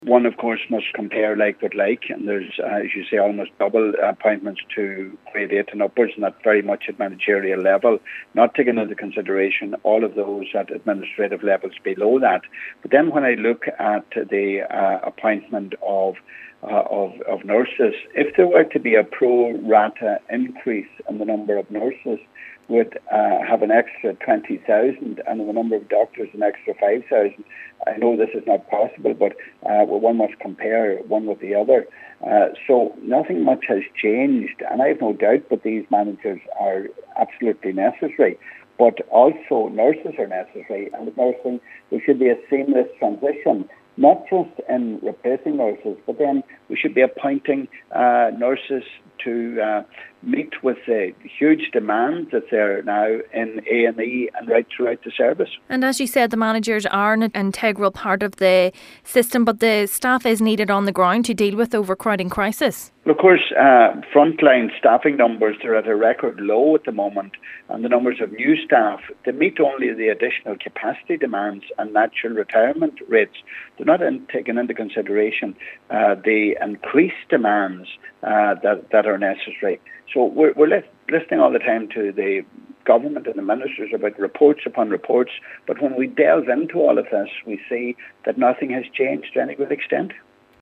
Donegal Deputy Pat the Cope Gallagher says while managerial roles are an integral part of the health service, staff is needed on the ground to tackle the health care crisis: